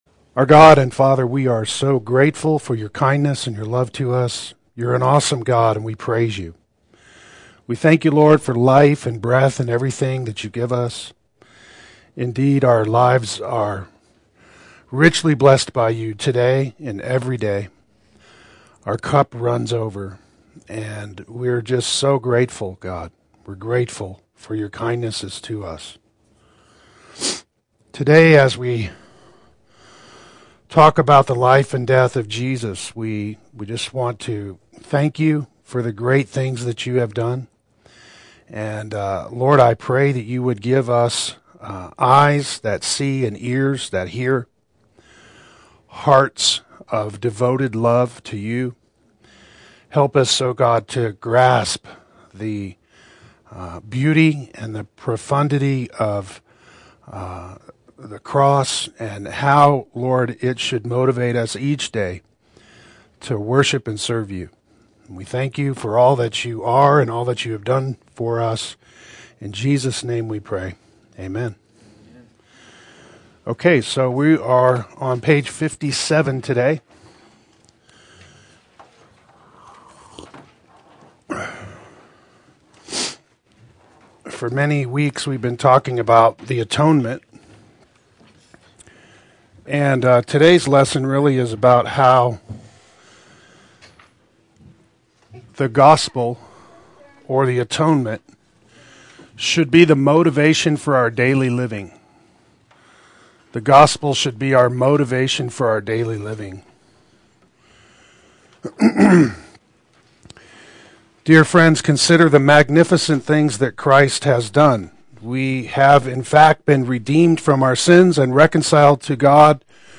Atonement Applied Adult Sunday School